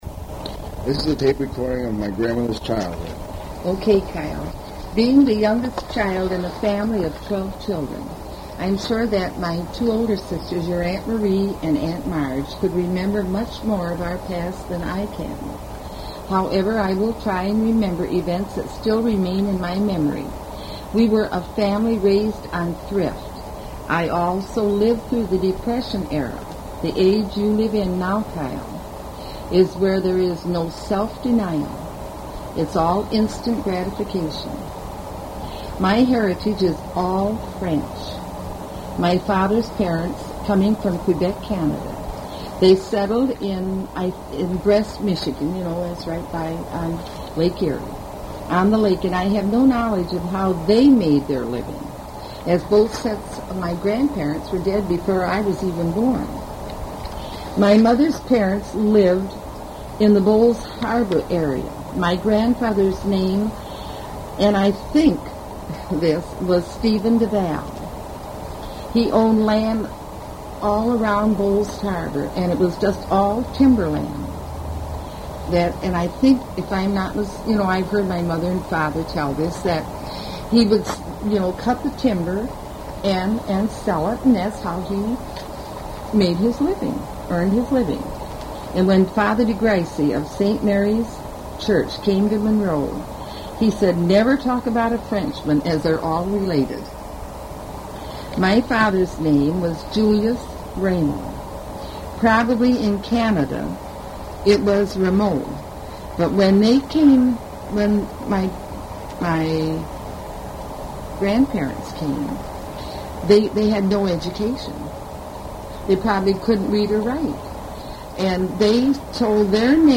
Recorded oral history
Interviews